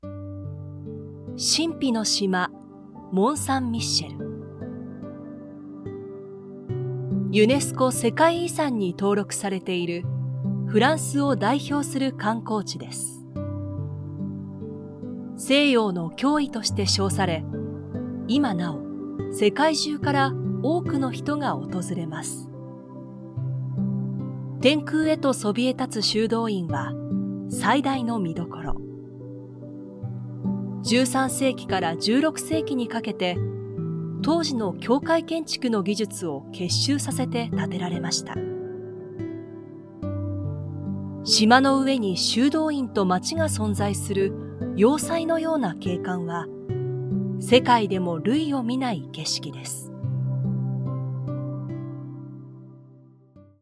Japanese voice over, Japanese voice, Japanese commercial, corporate, business, documentary, e-learning, product introductions
Sprechprobe: Sonstiges (Muttersprache):
Audioguide_1.mp3